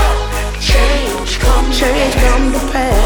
• Alternative
alternative rock